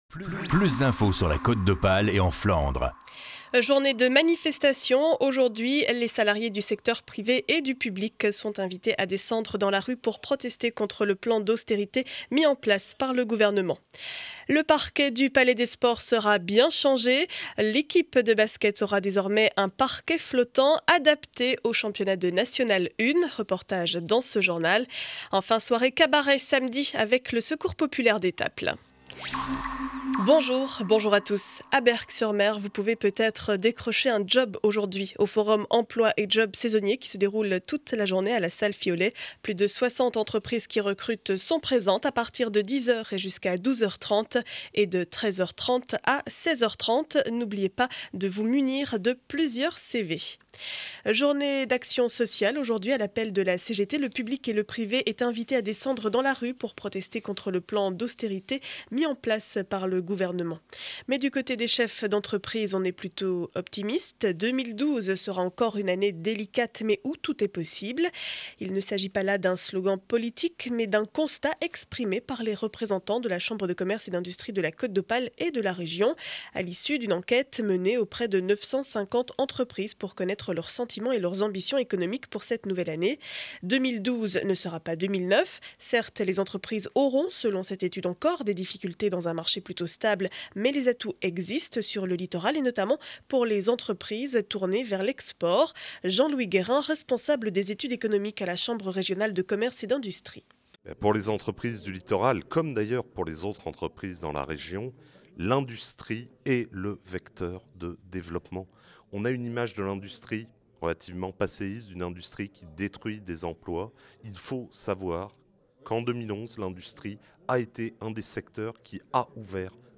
Journal du mercredi 29 février 2012 7 heures 30 édition du Montreuillois.